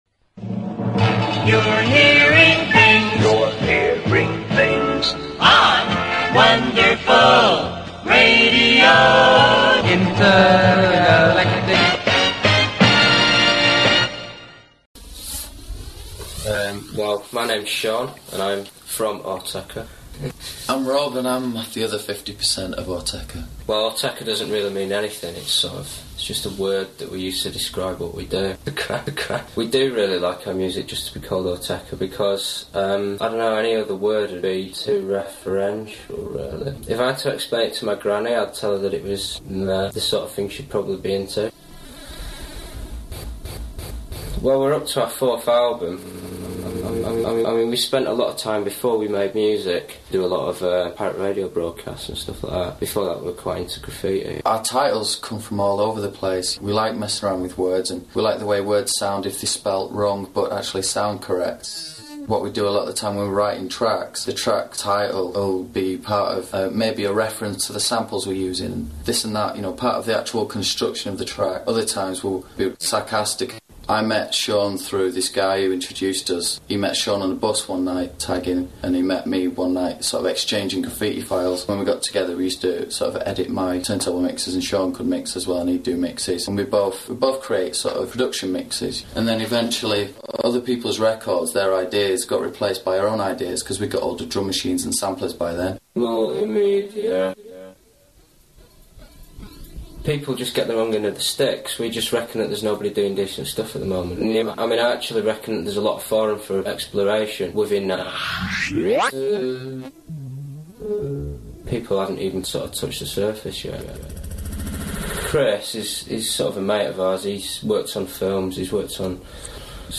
Autechre___2000_XX_XX_Germany_Berlin___01_interview.mp3